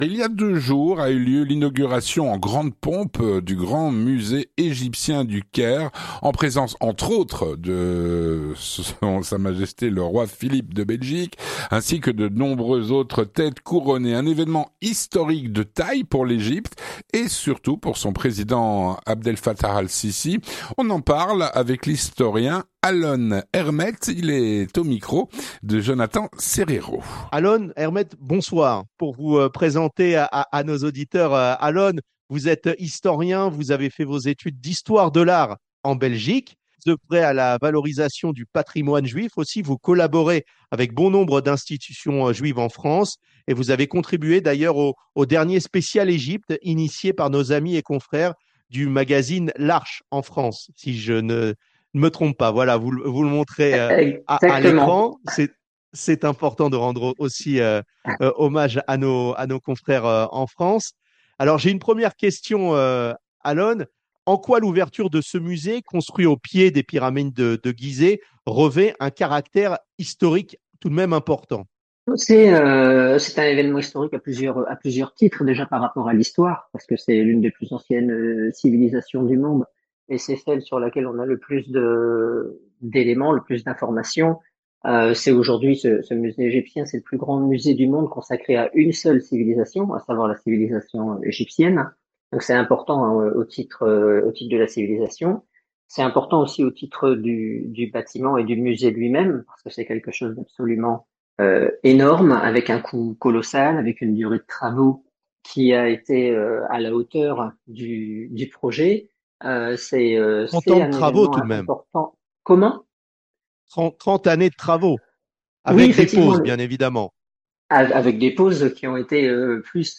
L'entretien du 18H - Il y a deux jours a eu lieu l'inauguration du Grand musée égyptien du Caire.